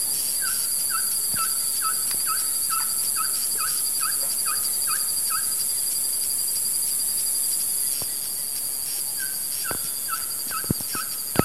Ferruginous Pygmy Owl (Glaucidium brasilianum)
Province / Department: Córdoba
Location or protected area: Las Varillas
Condition: Wild
Certainty: Recorded vocal